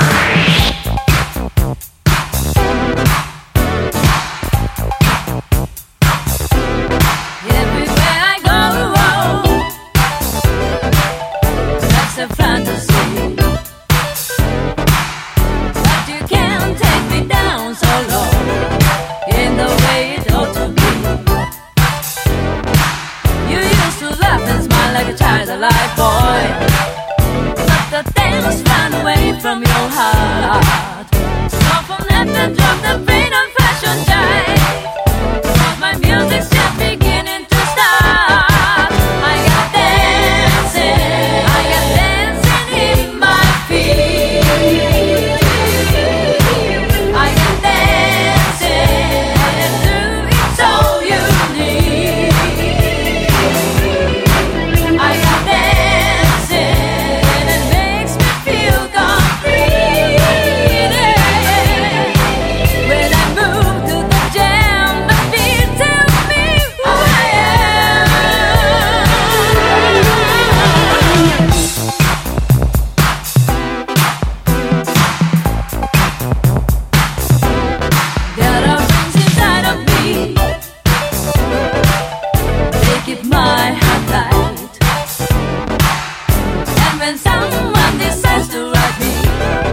JAZZ / JUG / COUNTRY BLUES
ひたすらに愉快な戦前ジャグ！ 1926～27年にシカゴで録音された
ヴァイオリンが舞いチューバがボコボコと踊る